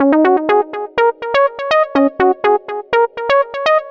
Klass Riff_123_Db.wav